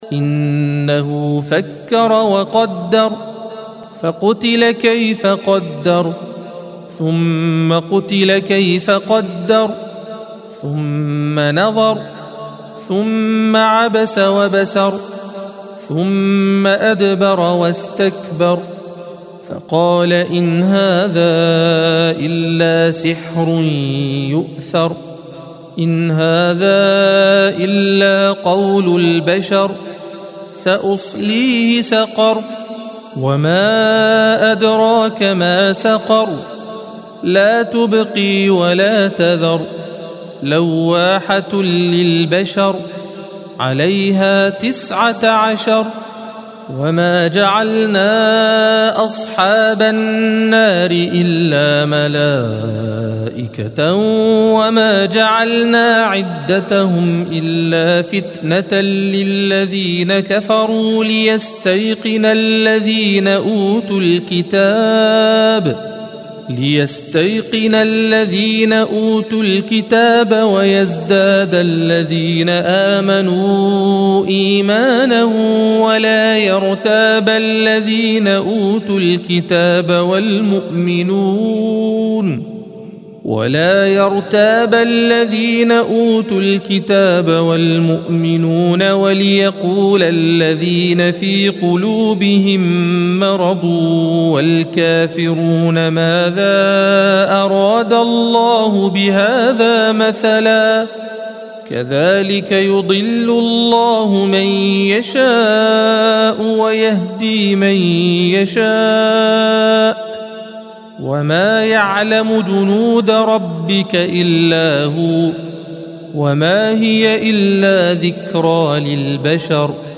الصفحة 576 - القارئ